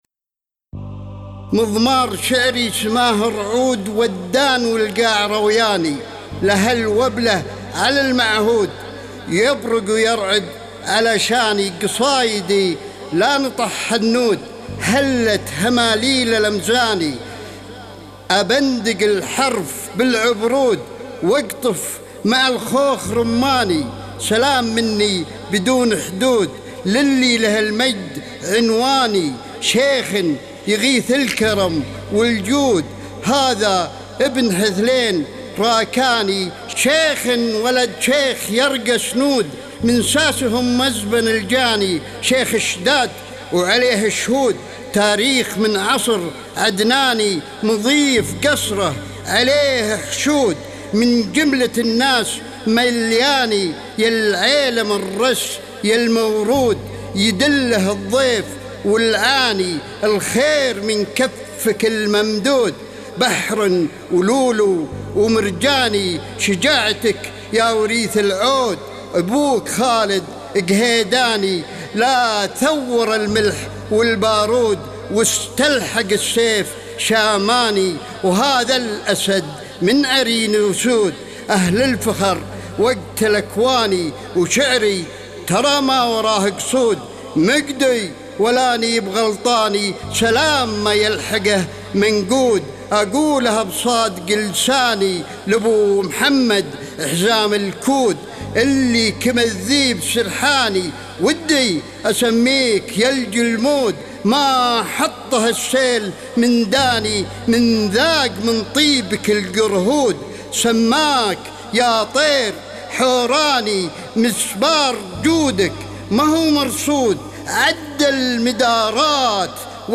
القاء